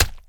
add new orange attack SFX